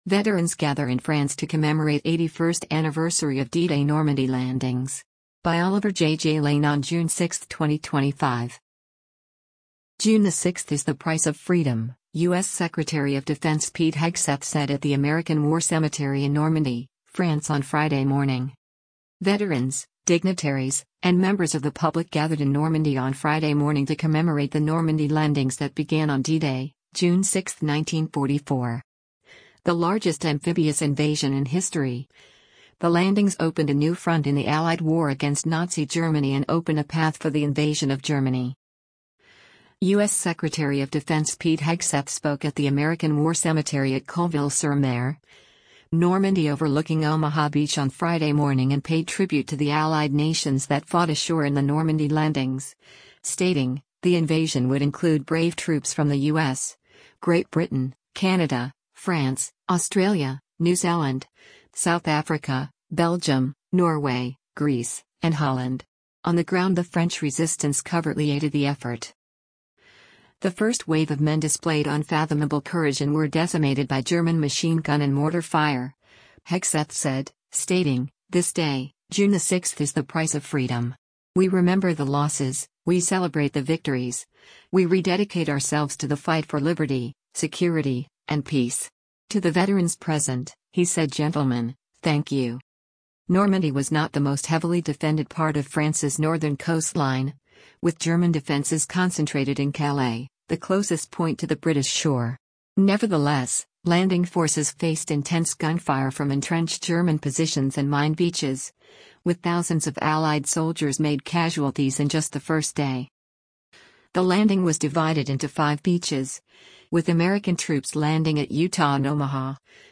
“June the sixth is the price of freedom”, U.S. Secretary of Defense Pete Hegseth said at the American War Cemetery in Normandy, France on Friday morning.